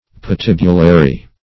Search Result for " patibulary" : The Collaborative International Dictionary of English v.0.48: Patibulary \Pa*tib"u*la*ry\, a. [L. patibulum a gallows: cf. F. patibulaire.] Of or pertaining to the gallows, or to execution.